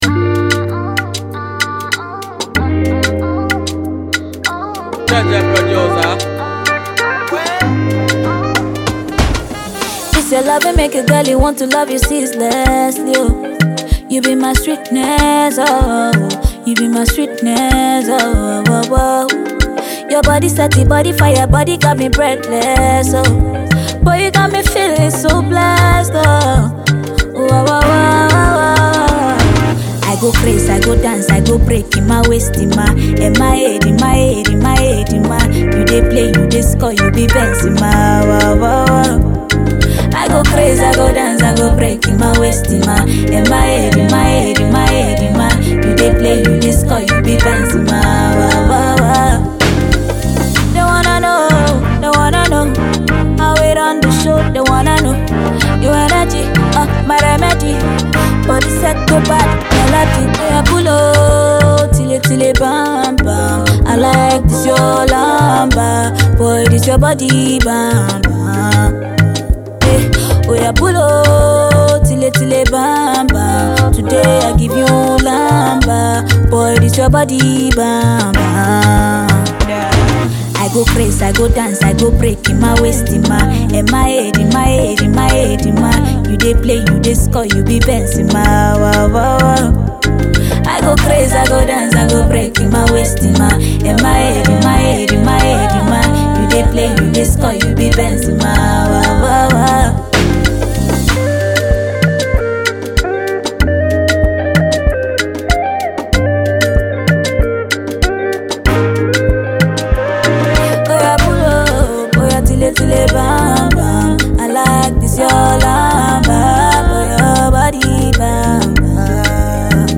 the new amazing female singer
new dance riddim